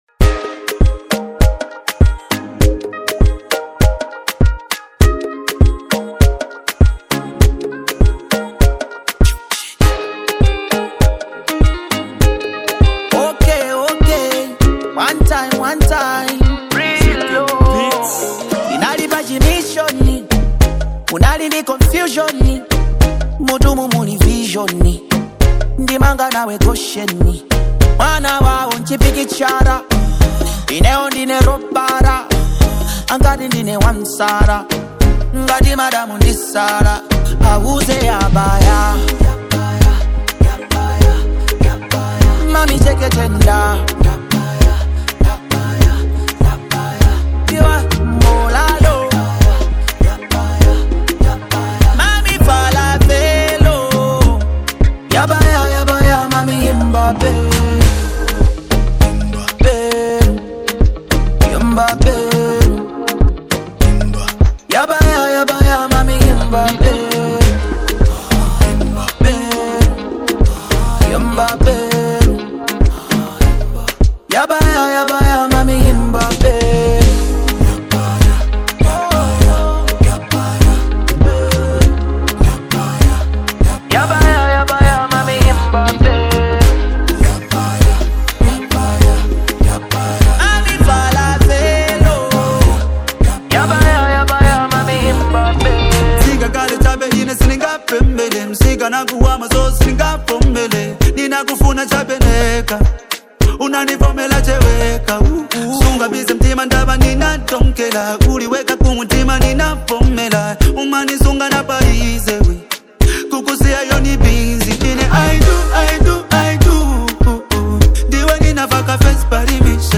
reggae and dancehall style
Afrobeat sound, creating a dynamic and engaging track.